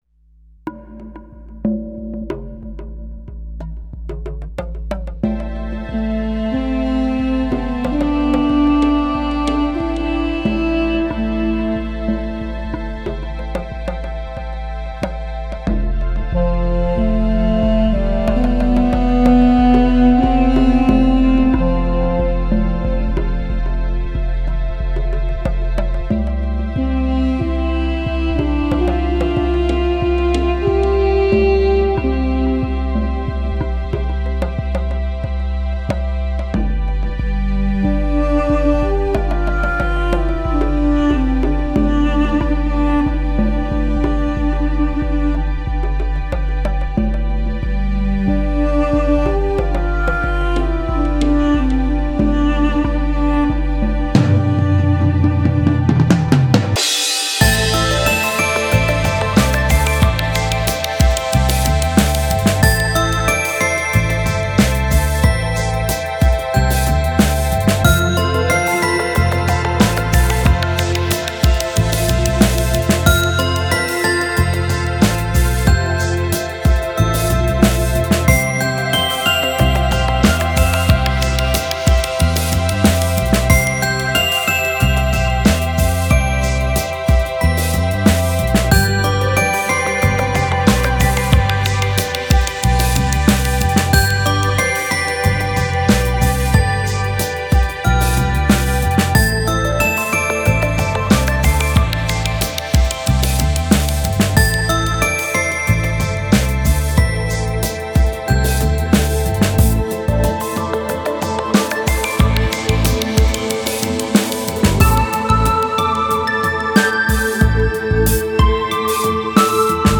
ορχηστρικές συνθέσεις
Lounge & Calm διάθεση